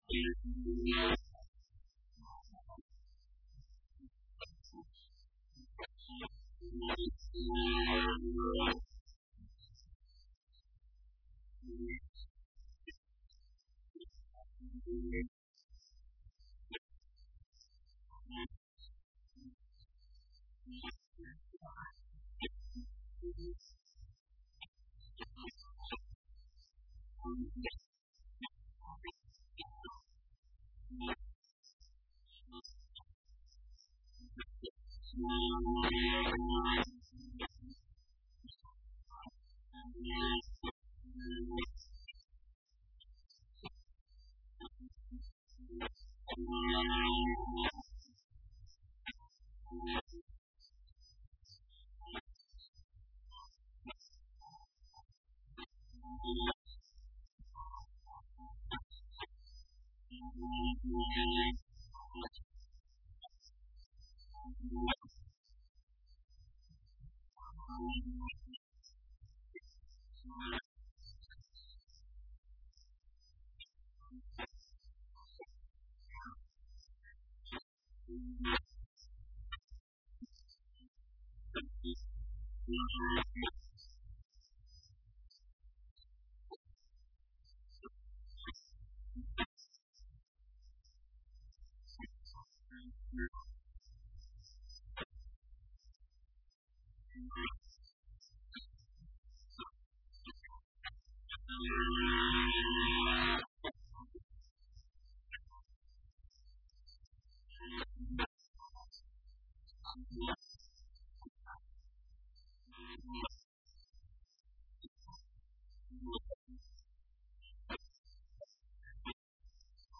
Intervenção do Secretário Regional da Saúde
Texto integral da intervenção do Secretário Regional da Saúde, Luís Cabral, proferida hoje na Assembleia Legislativa da Região Autónoma dos...